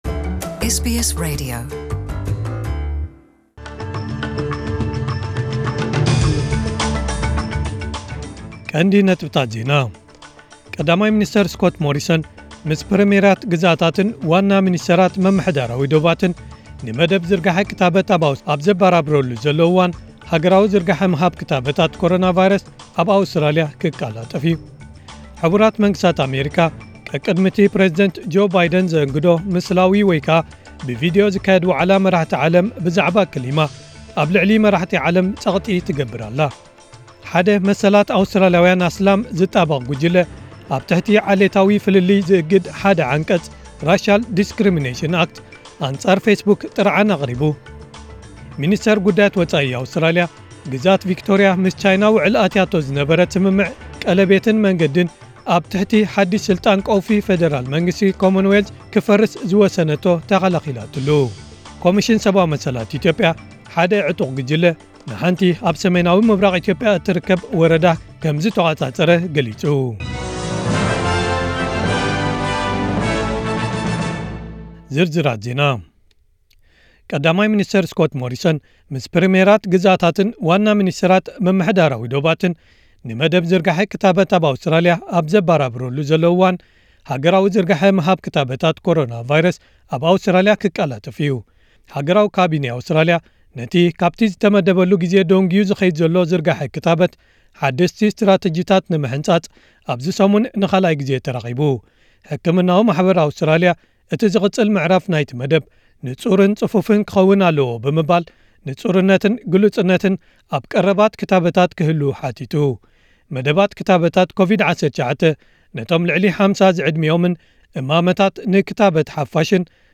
ዕለታዊ ዜና